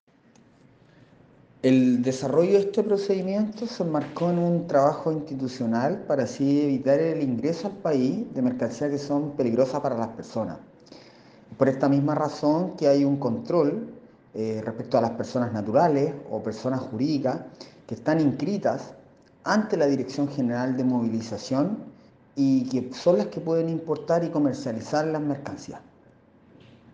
El Administrador de la Aduana de San Antonio, Ángelo Vergara, destacó el procedimiento donde se incautaron las armas.